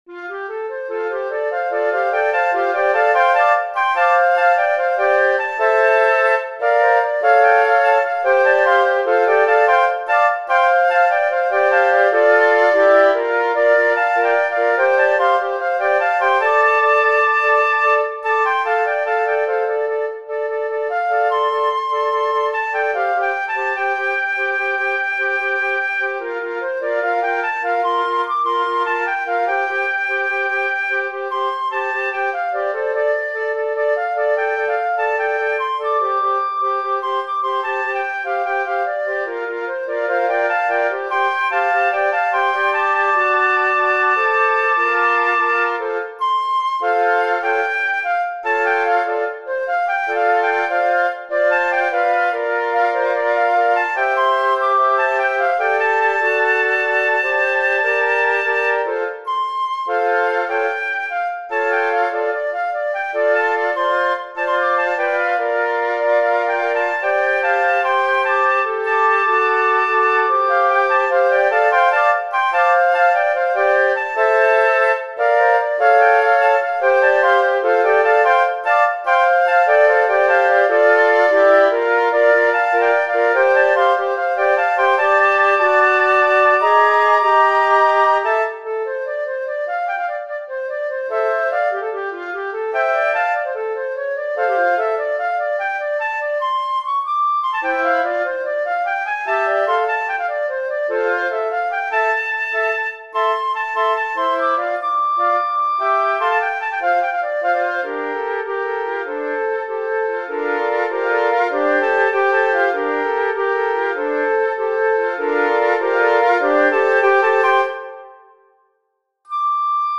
No.11 春颯（はるはやて） 【編成】尺八４（すべて一尺八寸管）  強烈な風と、それに乗って天高く 舞い上がる桜の花びらをイメージして作曲しました。 調を変え拍子を変え、明るくリズミカルに展開していきます。